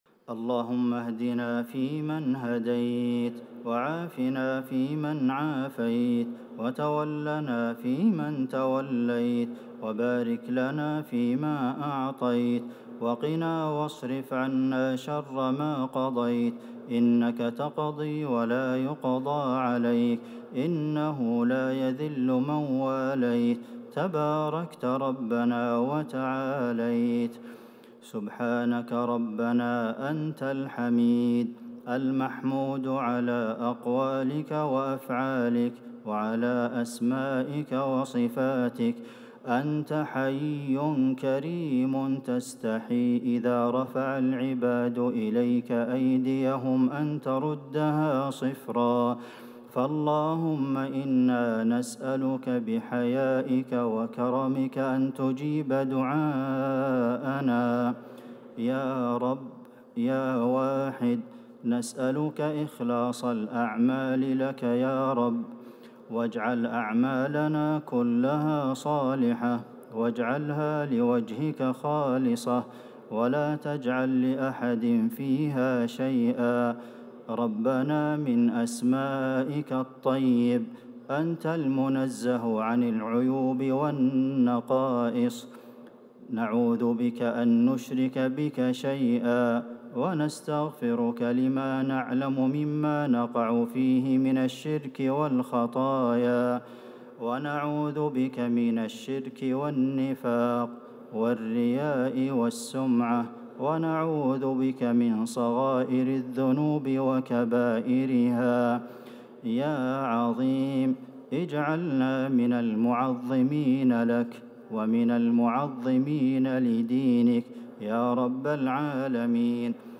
دعاء القنوت ليلة 26 رمضان 1444هـ | Dua for the night of 26 Ramadan 1444H > تراويح الحرم النبوي عام 1444 🕌 > التراويح - تلاوات الحرمين